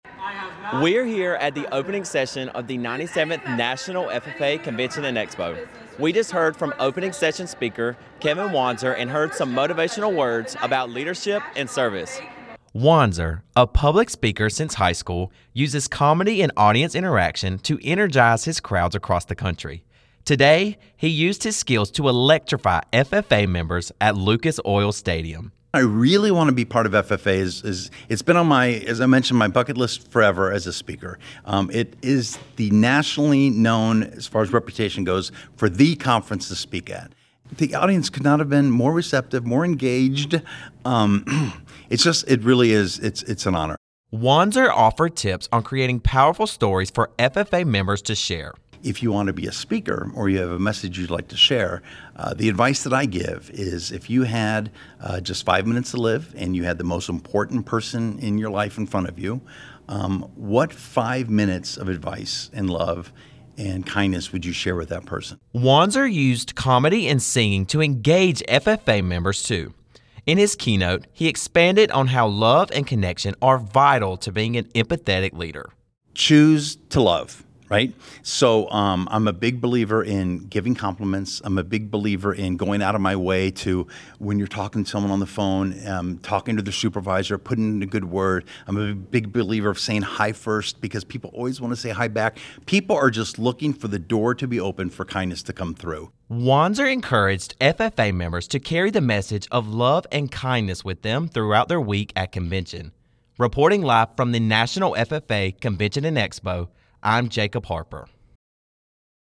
NRV24-032_keynote-speaker-story_RADIO.mp3